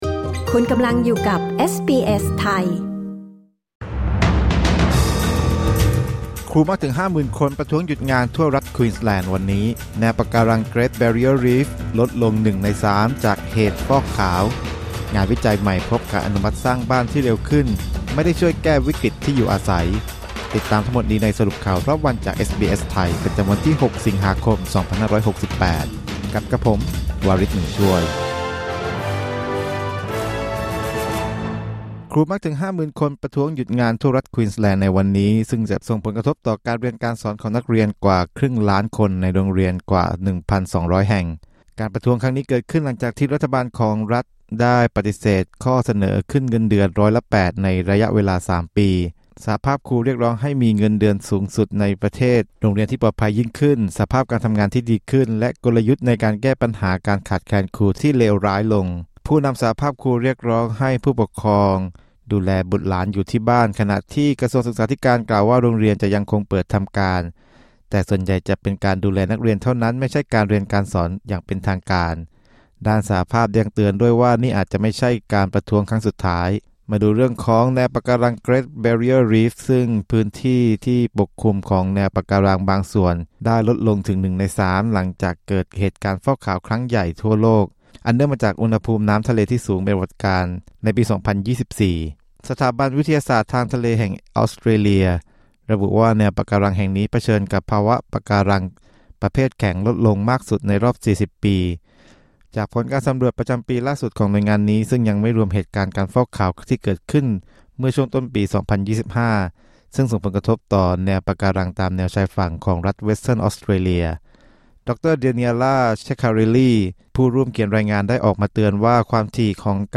สรุปข่าวรอบวัน 06 สิงหาคม 2568